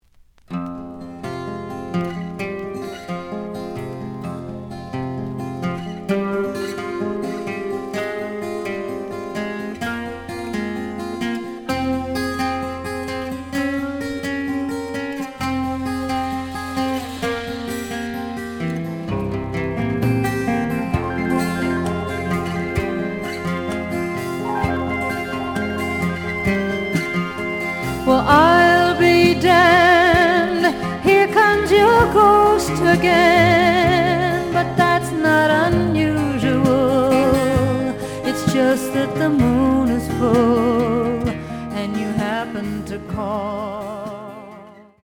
The audio sample is recorded from the actual item.
●Genre: Folk / Country